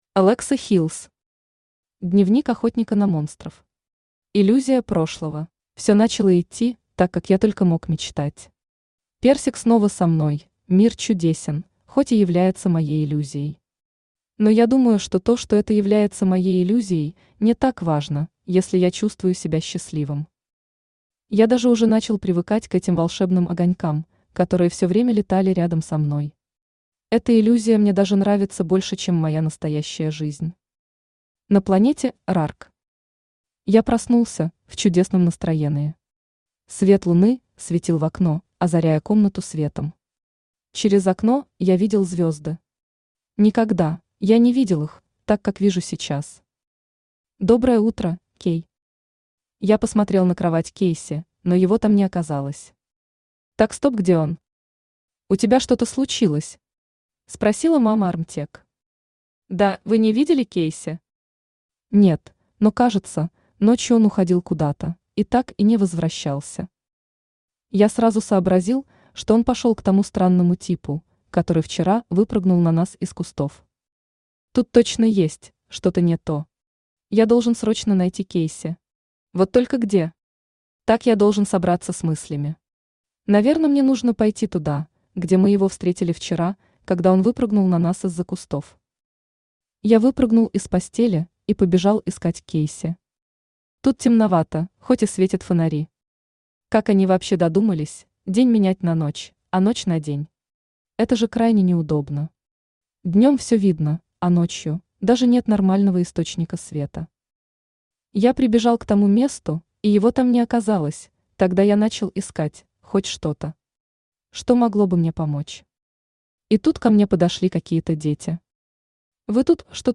Аудиокнига Дневник охотника на монстров. Иллюзия прошлого | Библиотека аудиокниг